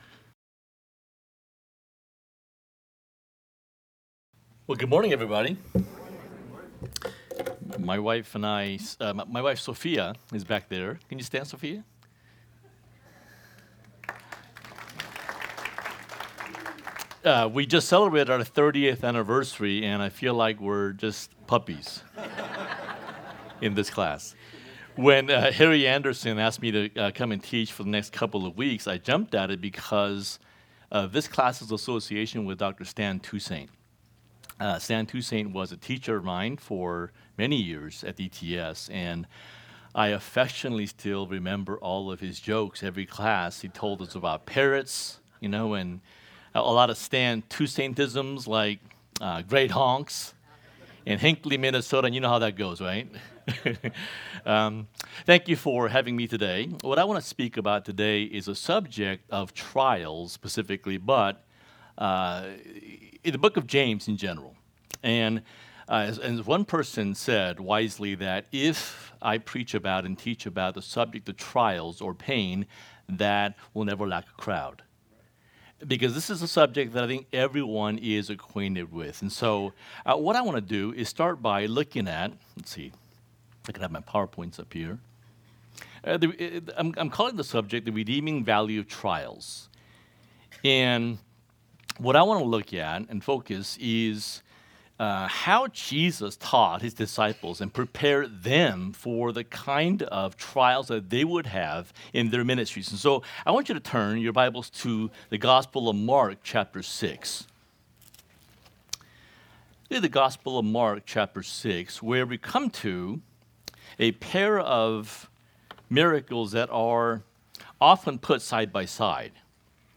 Marathon Fellowship Class The Perspective of Faith in Testing/Trials – James 1:1-18 May 27 2024 | 00:45:20 1x 00:00 / 00:45:20 Subscribe Share RSS Feed Share Link Embed